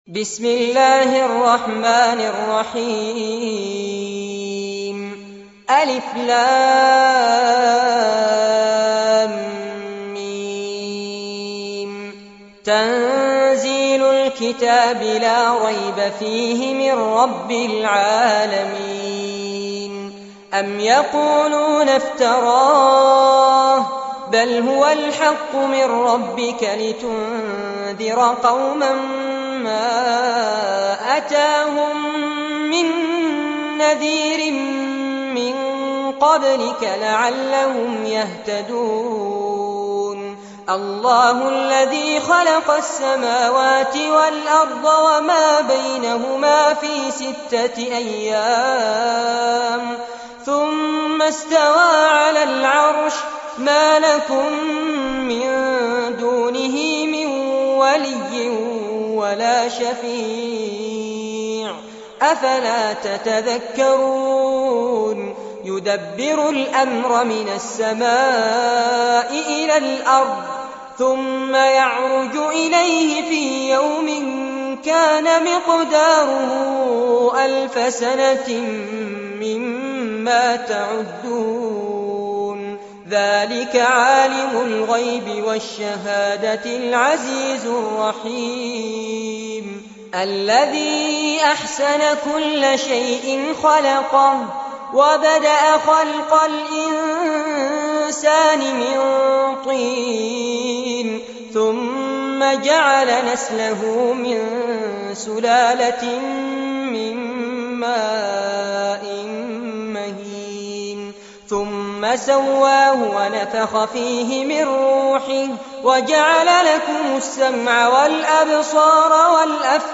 عنوان المادة سورة السجدة- المصحف المرتل كاملاً لفضيلة الشيخ فارس عباد جودة عالية